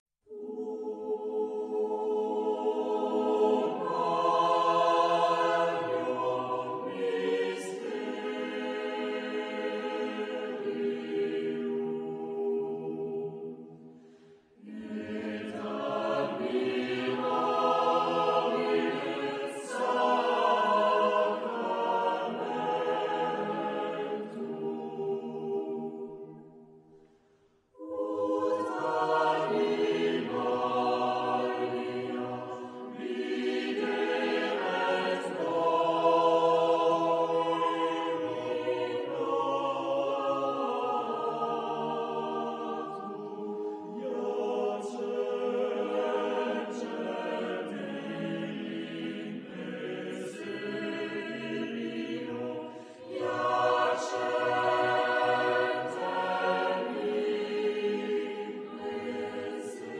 Genre-Style-Forme : Motet ; Sacré
Type de choeur : SATB  (4 voix mixtes )
Tonalité : la mineur